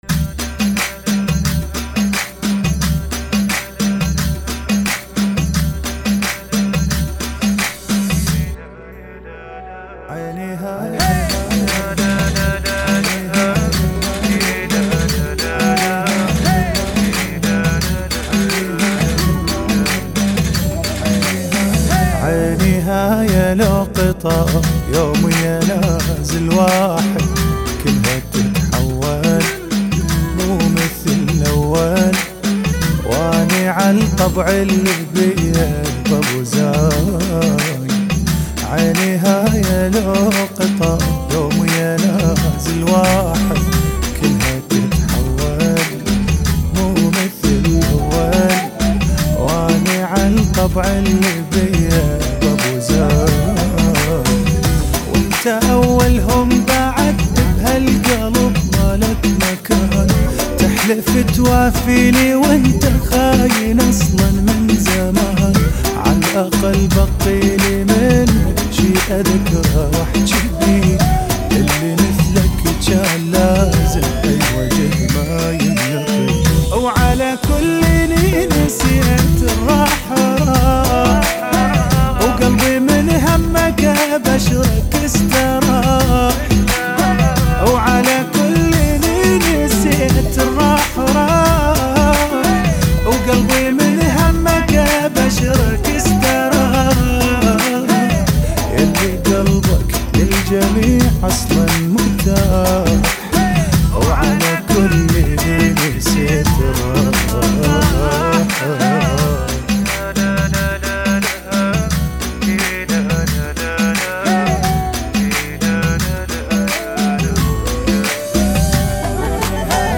[ 88 bpm ]